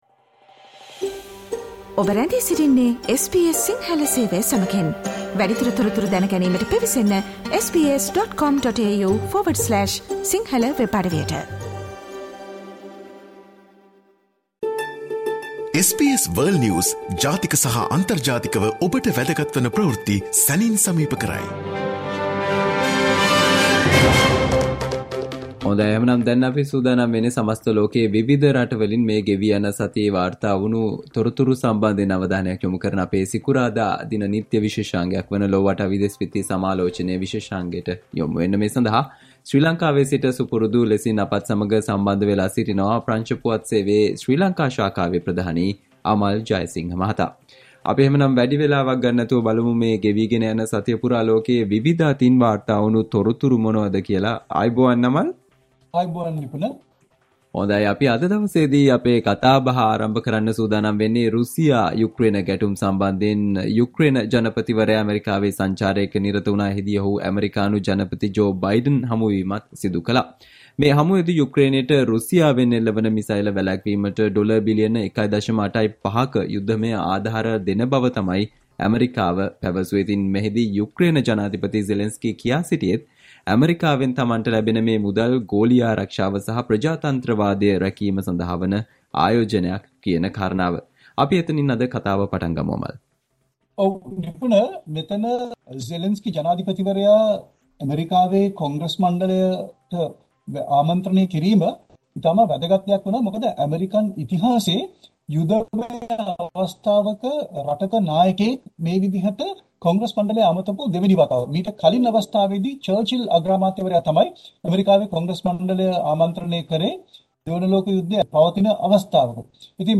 listen to the SBS Sinhala Radio weekly world News wrap every Friday Share